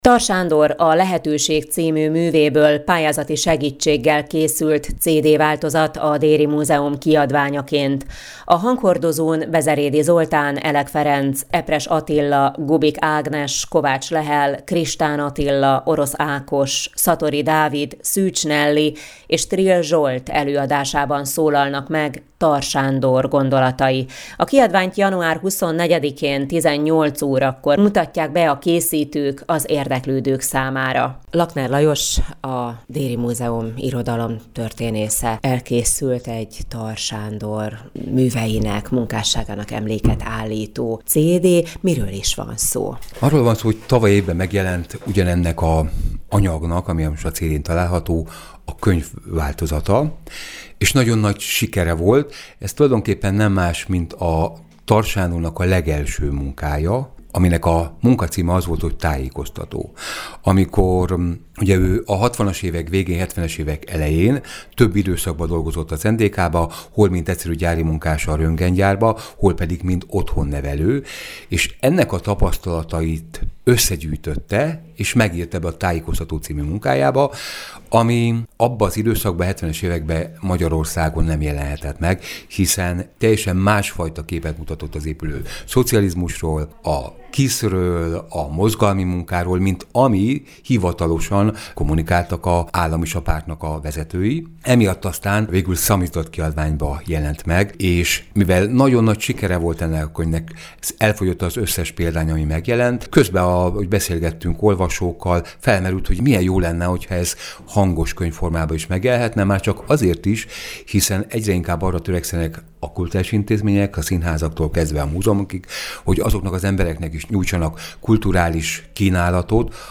hangoskönyv